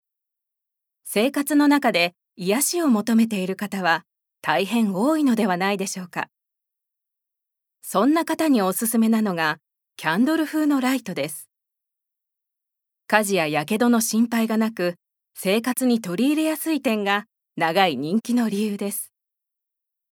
ボイスサンプル
ナレーション１